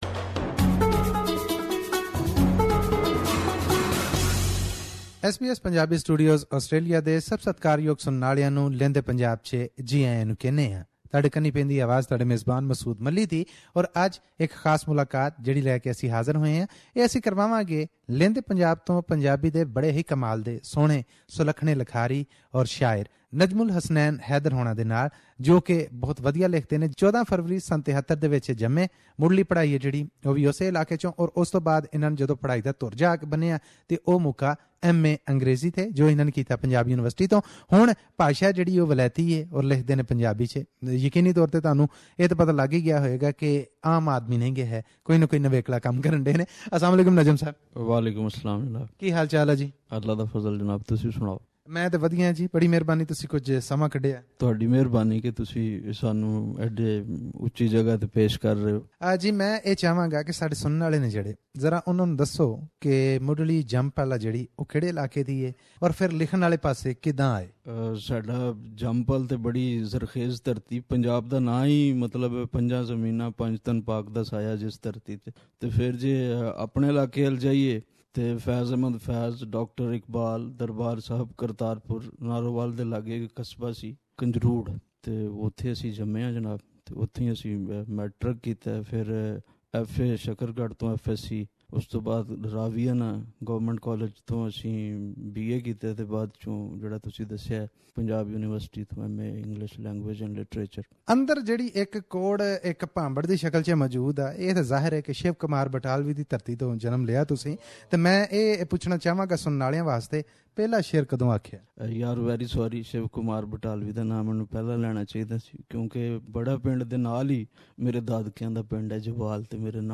In this interview he sings some of his choicest poems and credits his success to the wider thinking. Poetry is like a treatment to him when he suffers from surroundings.